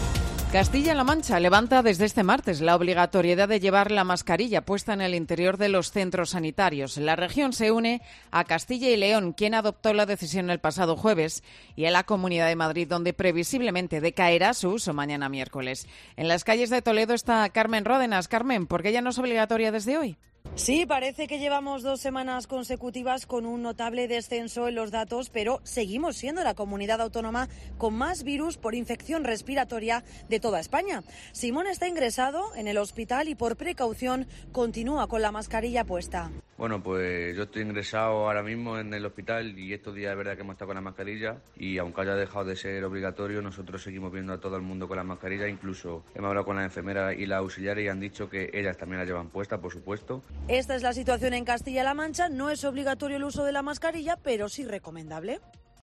COPE Toledo, anuncia el fin de las mascarillas obligatorias en Castilla-La Mancha